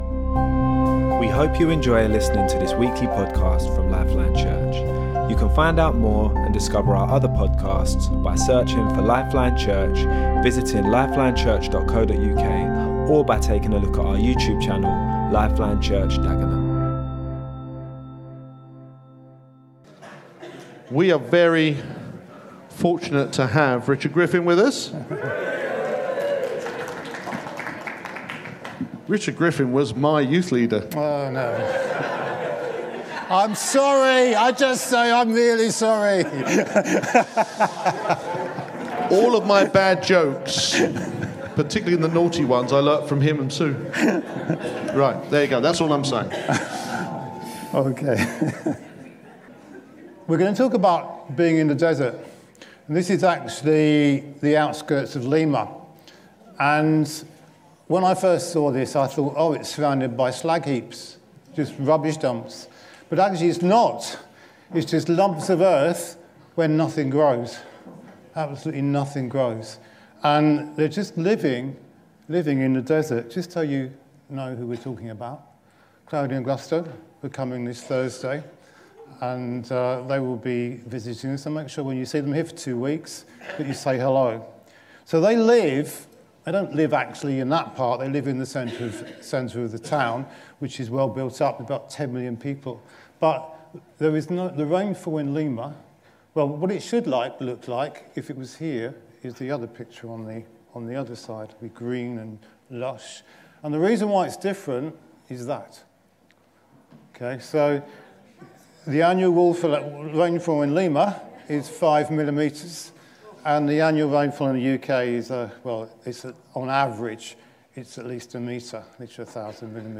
Talks from LifeLine Church's weekly meeting - released every Monday